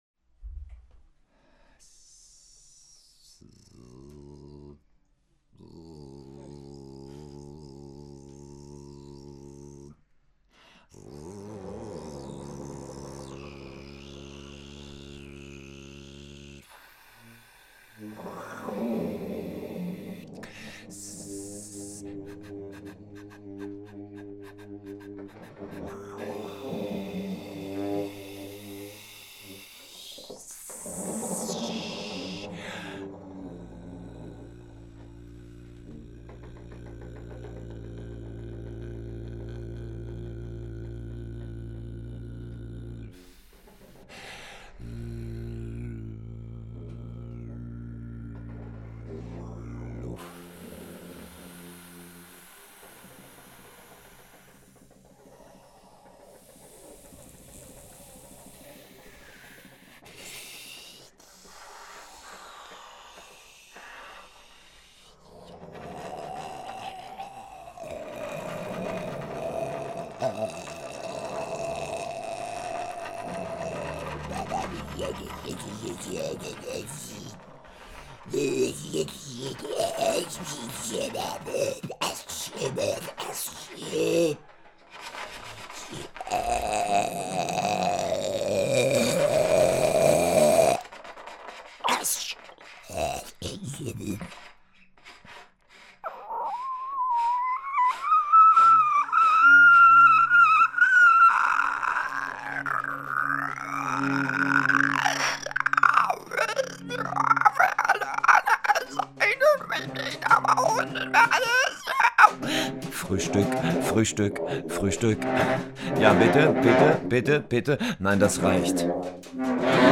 trombone
baritone saxophone
voice
field recordings at SuperGau-Festival Lungau (May 2023)